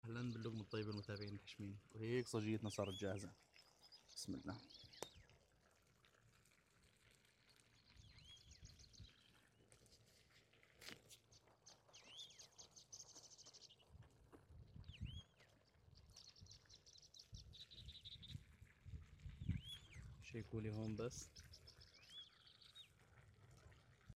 اجواء جداً خرافيه أصوات العصافير sound effects free download
اجواء جداً خرافيه أصوات العصافير مع الضباب والبحر والشجر كل هاد بس في صلاله سلطنه عمان